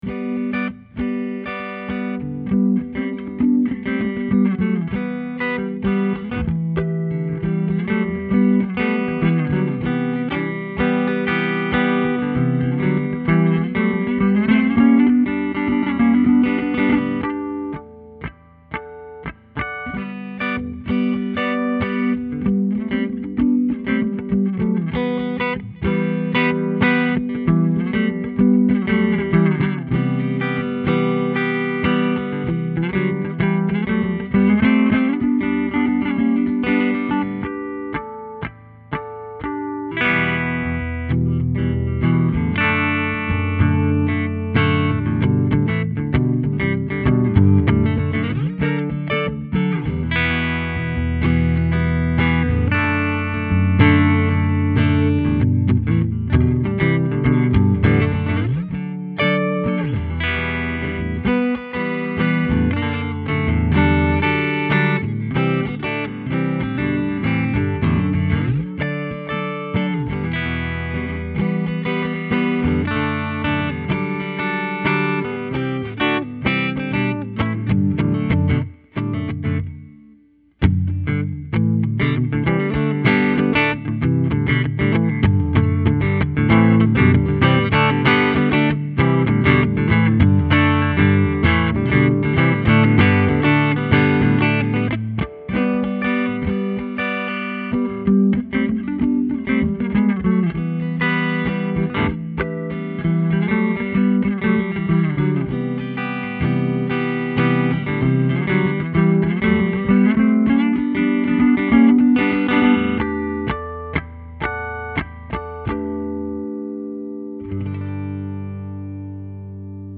Here are 12 quick, 1-take MP3 sound files of myself playing this guitar, to give you an idea of what to expect. The guitar has great tone, sustain, and body, and is also capable of some nice controlled feedback effects. These tracks are all recorded using a Peavey Studio Pro 112 amp with a a Sennheiser MD441 mic, recorded straight into a Sony PCM D1 flash recorder, and MP3s were made in Logic, with no EQ or effects.
(Original in G)
It also has this Beatles-era psychadelic vintige vibe to it using the clean sounds, and it has a very nice jangly sound to the basses, and the trebles ring out nicely to give the guitar a wonderful clarity and deffinition.